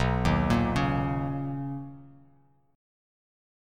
BM9 Chord
Listen to BM9 strummed